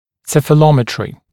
[ˌsefəˈlɔmɪtrɪ][ˌсэфэˈломитри]цефалометрия